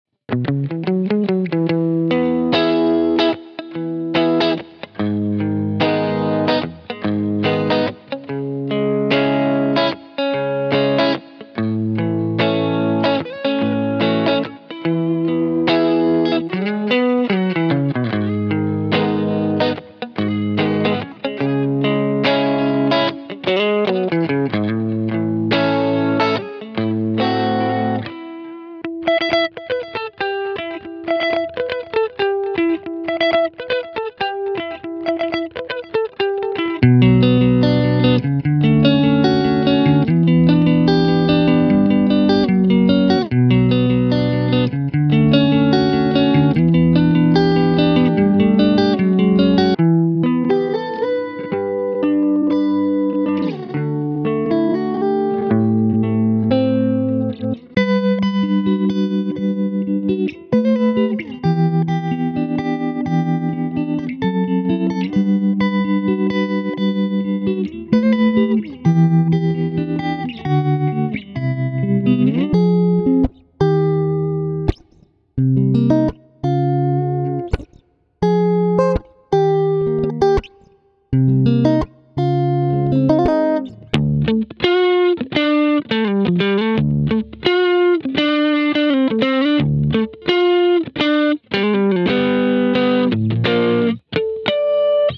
54 Guitar Riffs: Dive into an extensive library of guitar loops that cater to various genres, each meticulously recorded to capture the authentic feel and tonal richness of live guitar.
African: Vibrant and rhythmic, these loops bring the infectious grooves and intricate melodies of African guitar music to your tracks, perfect for adding a touch of global flair.
Reggae: Laid-back and groovy, these reggae loops capture the essence of island vibes, with syncopated rhythms and smooth, skanking chords that define the genre.
Rock: Raw and powerful, the rock guitar loops deliver high-energy riffs and driving rhythms that are essential for crafting punchy, hard-hitting tracks.
Neo-Soul: Smooth and soulful, the neo-soul guitar loops bring lush, jazzy chords and silky riffs that add a sophisticated, contemporary feel to your music.
Lo-Fi: Chill and mellow, the lo-fi guitar loops offer a warm, nostalgic sound, ideal for creating laid-back tracks with a relaxed, intimate atmosphere.
Each loop is recorded with pristine clarity and dynamic expression, ensuring that your tracks stand out with the rich, authentic sound of live guitar.